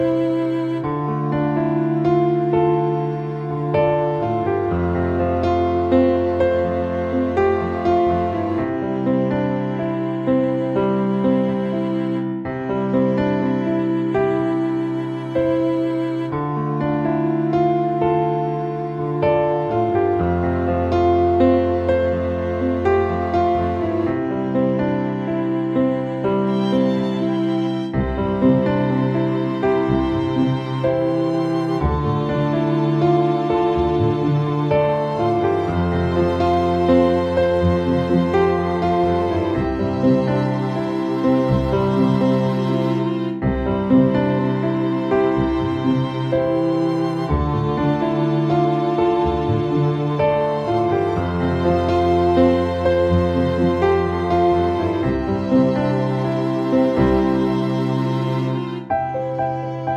Up 3 Semitones For Male